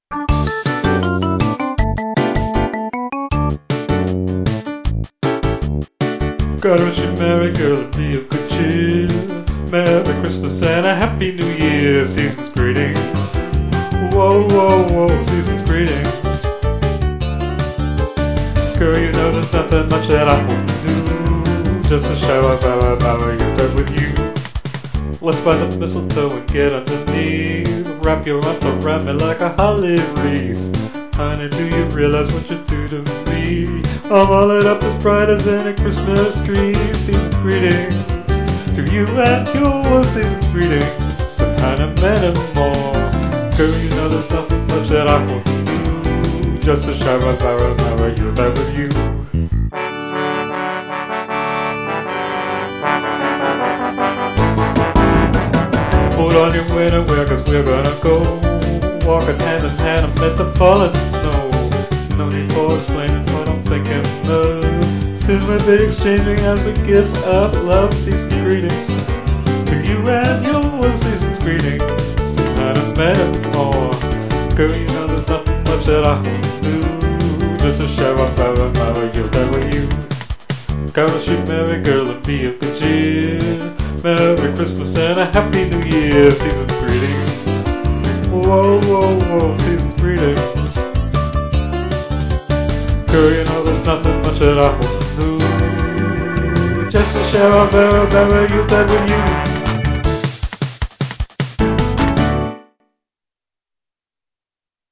fast 4/4 50's rock, male or female voice